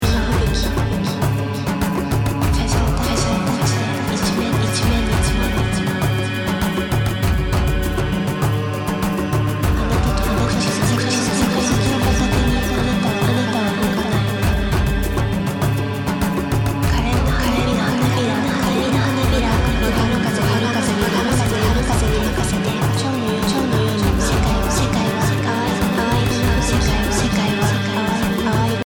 528hz BPM100-109 Game Instrument Soundtrack
Royalty Free Music（無料音楽）
BPM 100